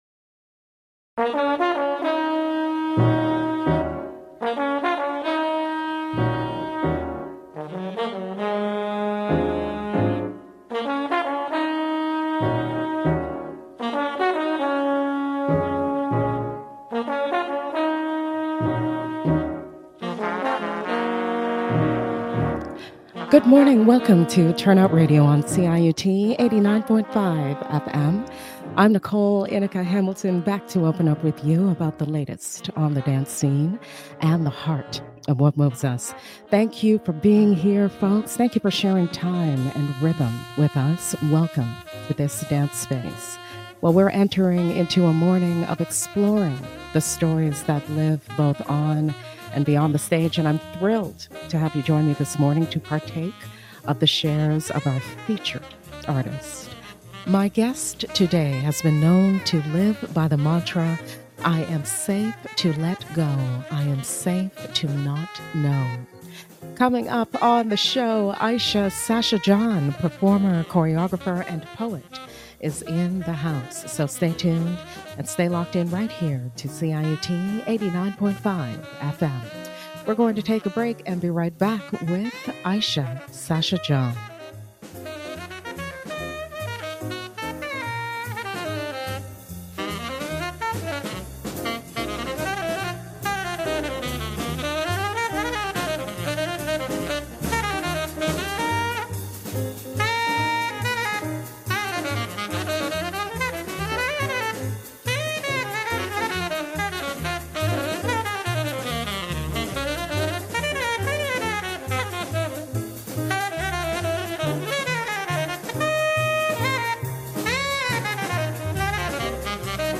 ive on CIUT 89.5 FM